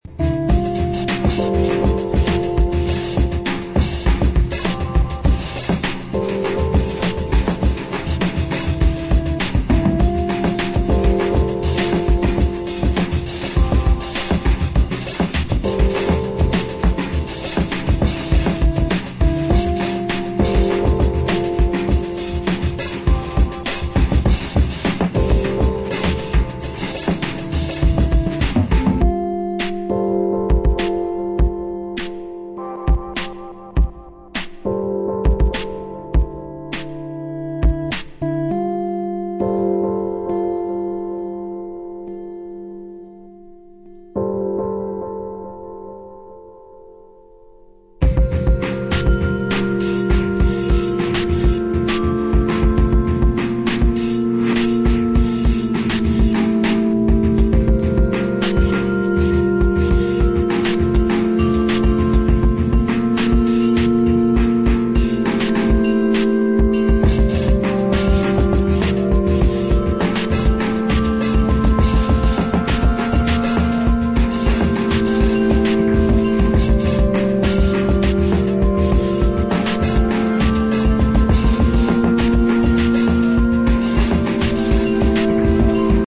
abstract cinematic electronica
Electronix